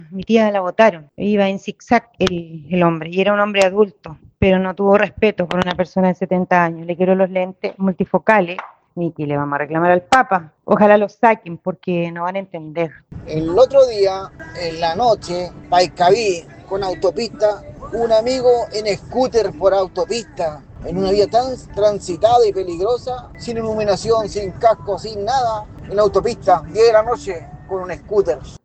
Se han registrado algunos problemas, tal como los describieron auditores de Radio Bío Bío. Choque a peatones, circulación a alta velocidad por vías no aptas y usuarios sin casco ni luces.